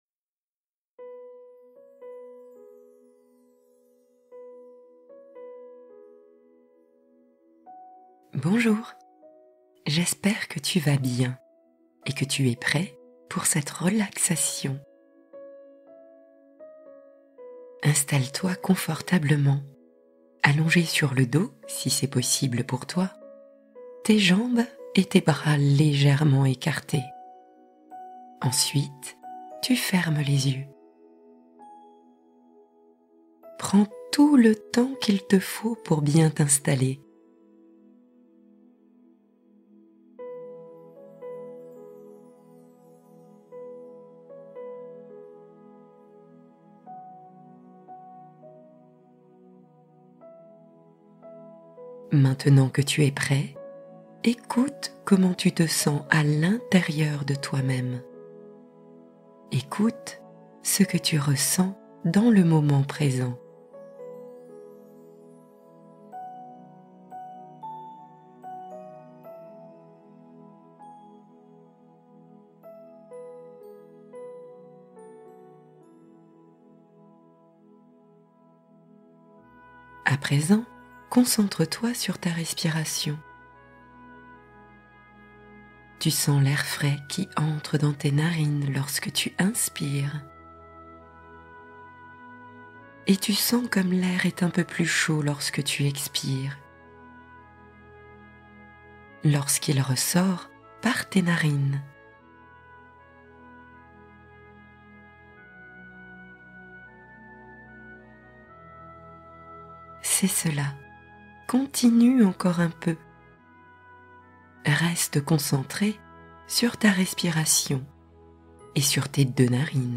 Détente corps-esprit progressive : libération guidée des tensions accumulées